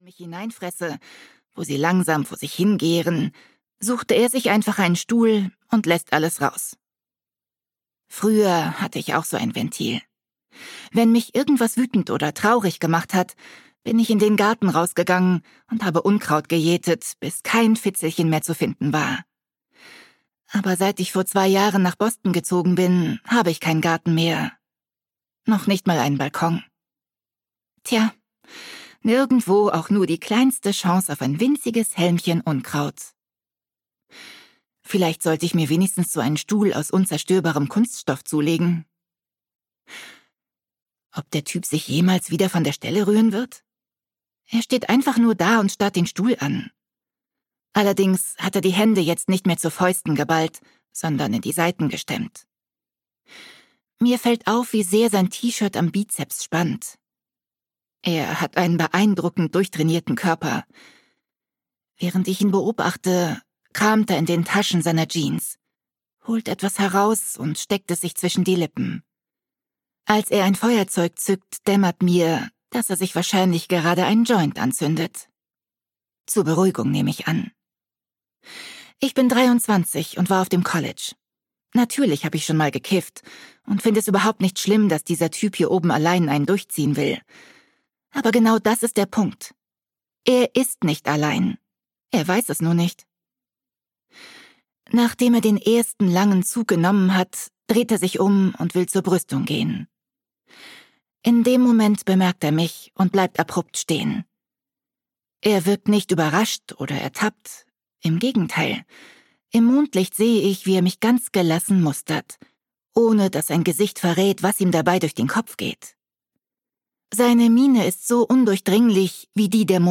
Nur noch ein einziges Mal (DE) audiokniha
Ukázka z knihy